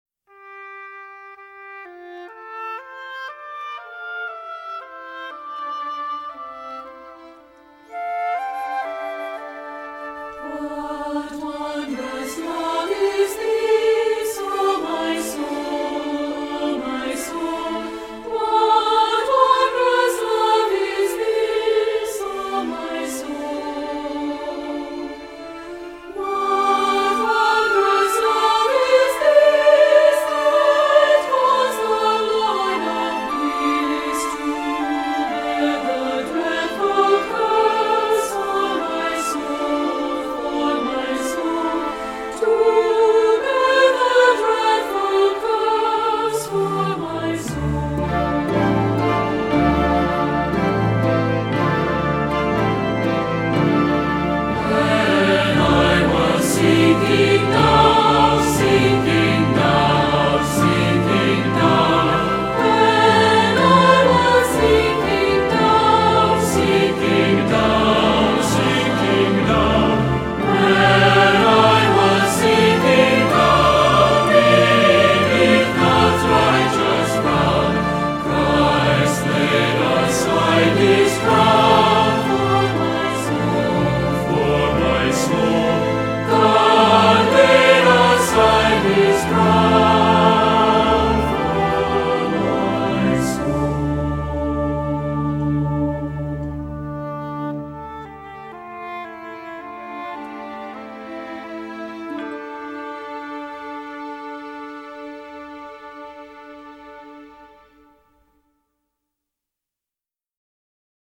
Composer: American Folk Hymn
Voicing: SATB